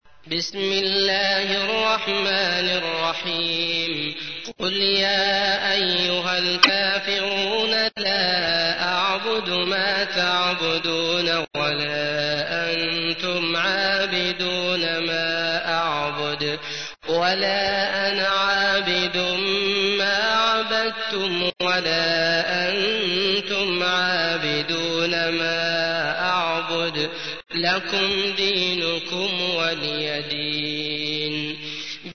تحميل : 109. سورة الكافرون / القارئ عبد الله المطرود / القرآن الكريم / موقع يا حسين